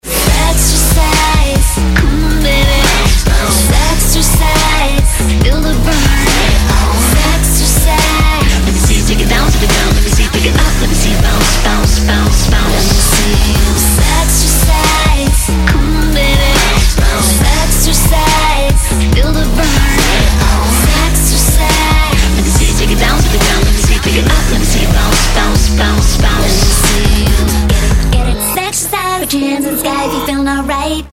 • Качество: 192, Stereo